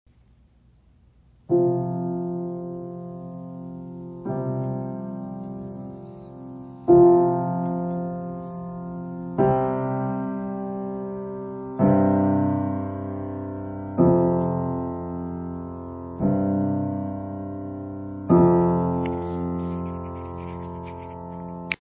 The first set goes up in the treble clef from C to F (fourth) and then to G (fifth). The second set goes down in the bass clef from C to G (fourth) and then to F (fifth).
The "Perfect Fourth" and "Perfect Fifth" in music